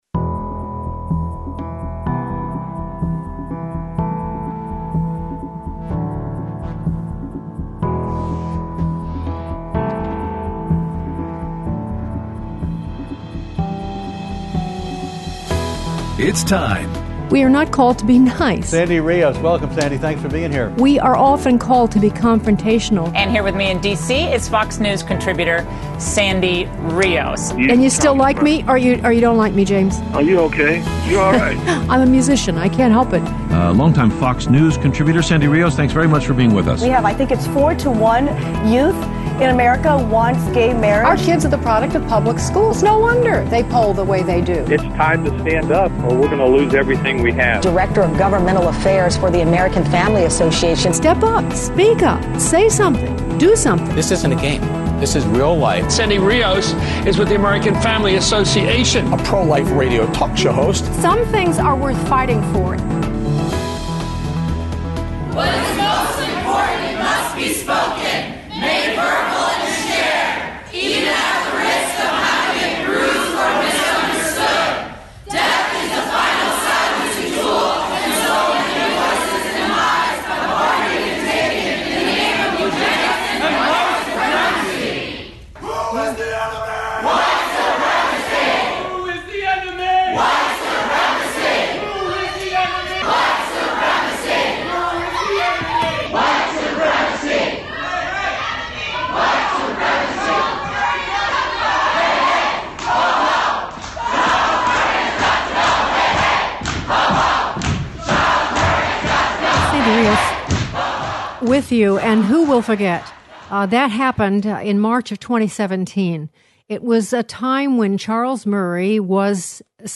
Interview
Aired Friday 5/3/19 on AFR 7:05AM - 8:00AM CST